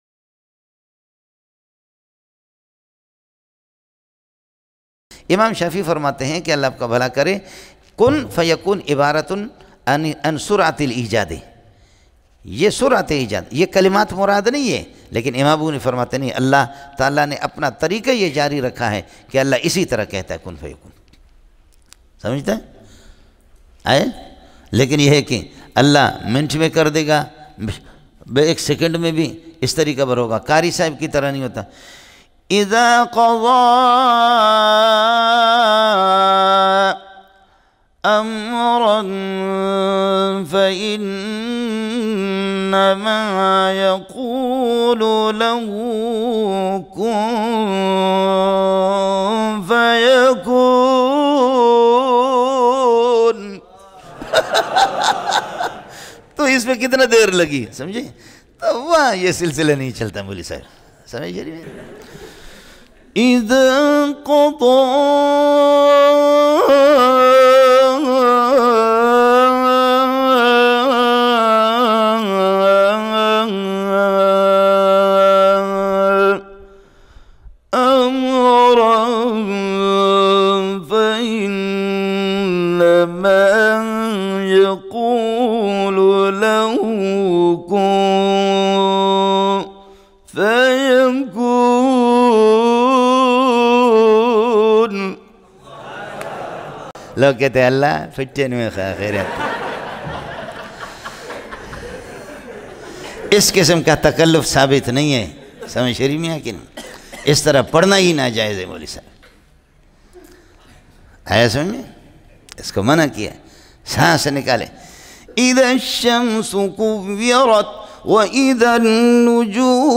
Imitating Qurra mp3